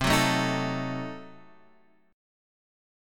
C Diminished 7th